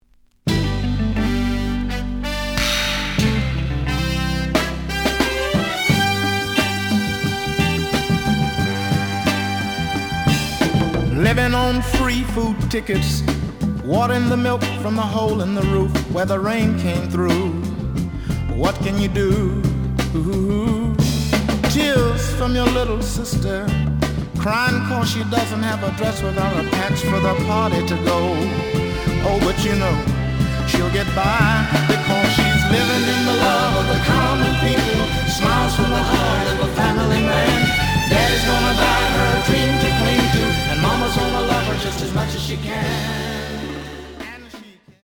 The audio sample is recorded from the actual item.
●Genre: Soul, 60's Soul
Slight damage on both side labels. Plays good.)